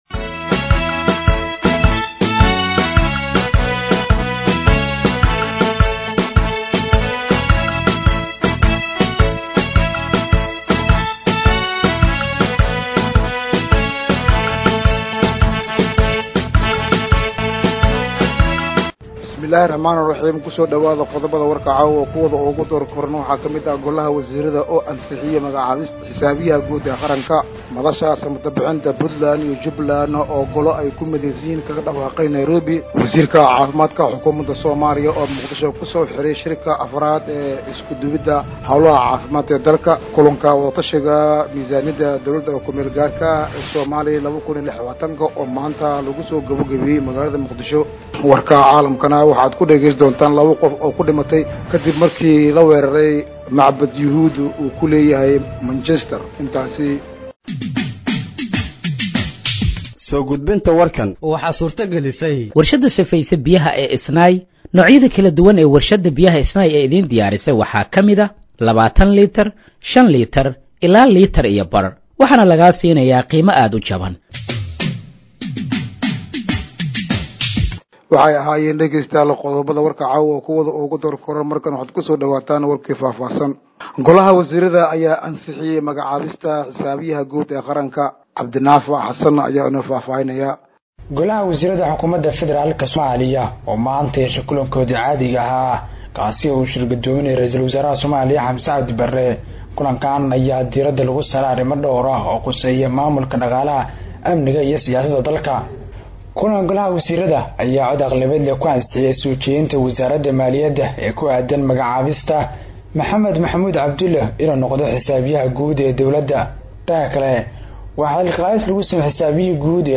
Dhageeyso Warka Habeenimo ee Radiojowhar 02/10/2025